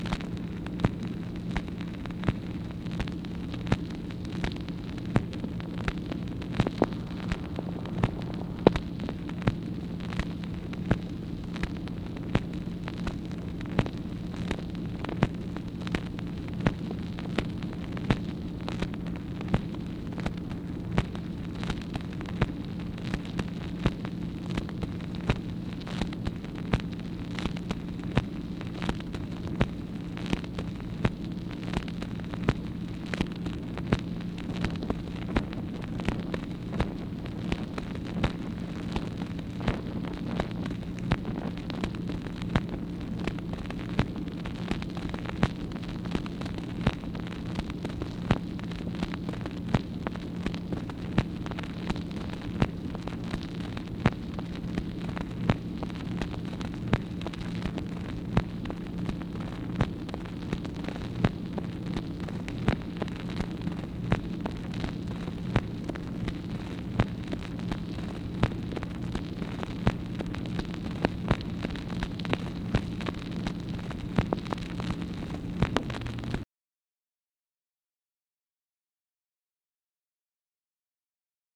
MACHINE NOISE, April 30, 1964
Secret White House Tapes | Lyndon B. Johnson Presidency